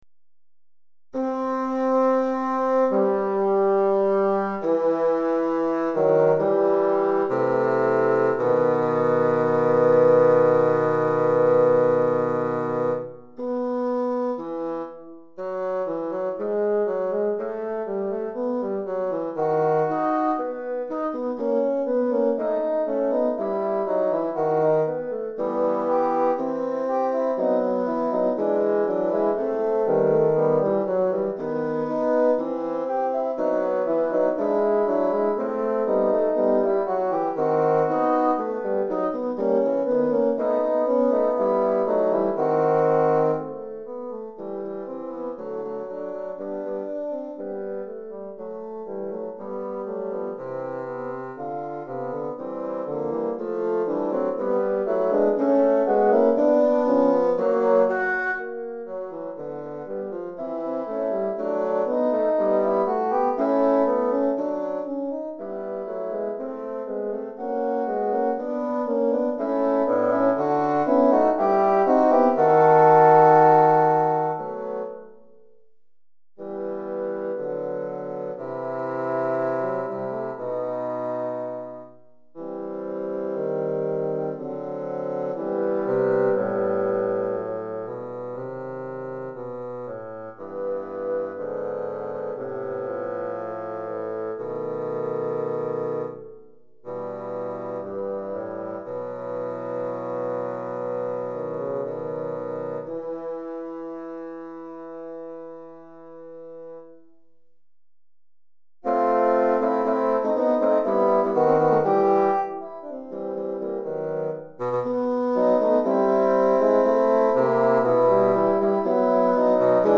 3 Bassons